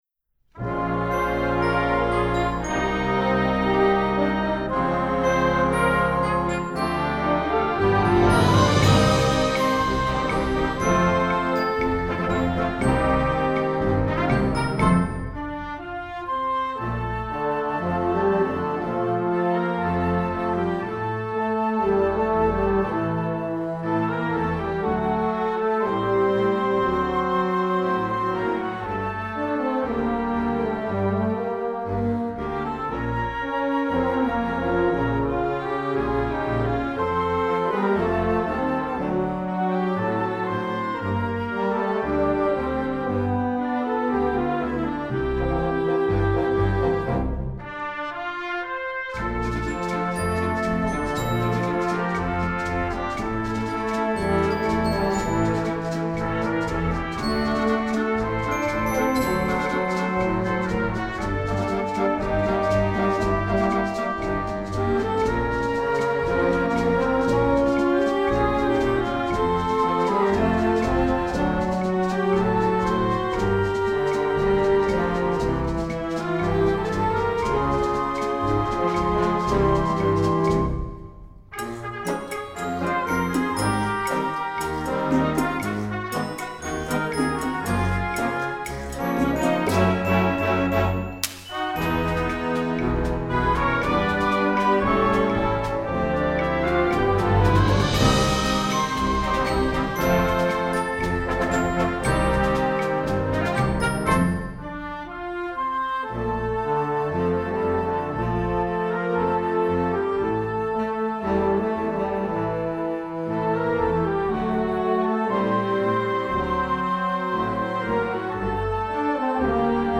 Instrumentation: concert band